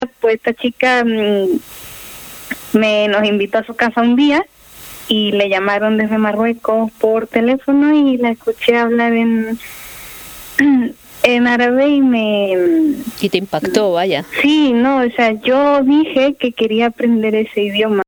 Musicalidad en su habla, relajada, desvela lentamente un espíritu inquieto, divertido, colaborador.
Una entrevista